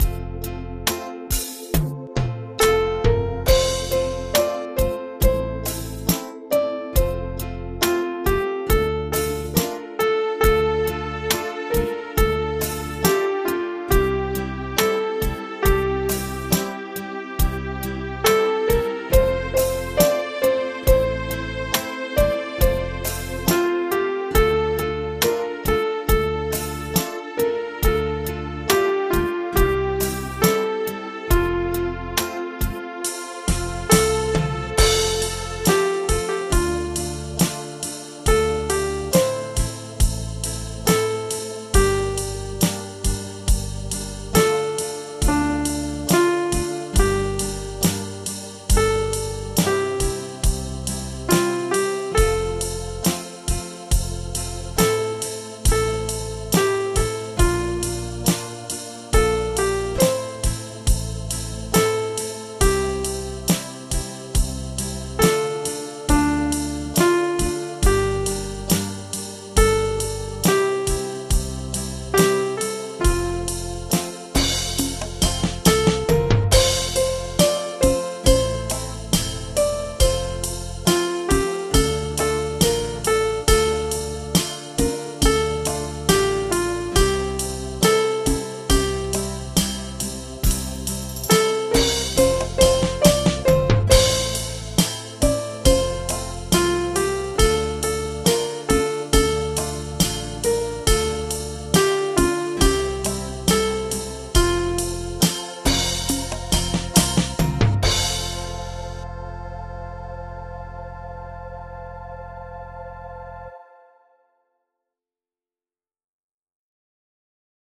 Audio Midi Bè 01: download